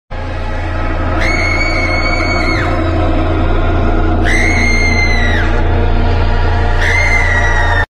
creepy black Cat . 🐈‍⬛ sound effects free download